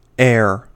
File:En-us-air.ogg
English: Pronunciation of the term in US English